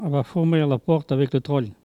Langue Maraîchin